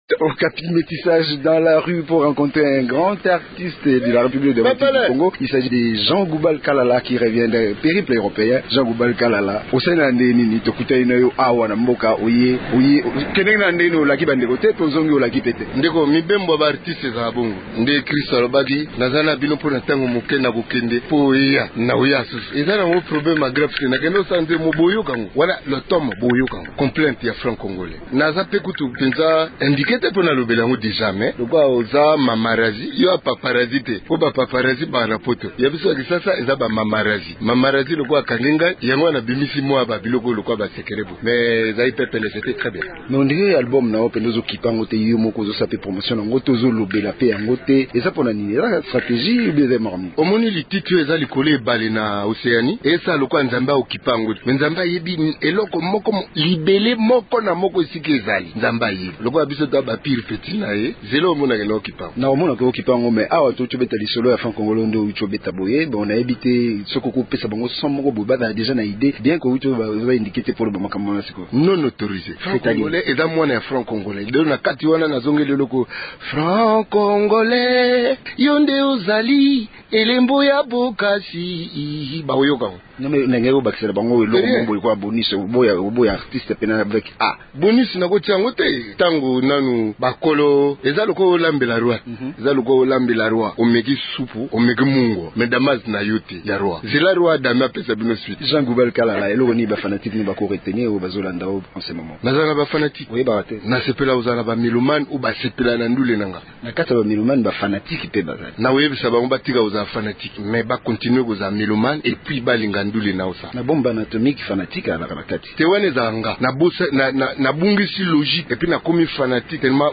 l’a rencontré dans les rues de kinshasa.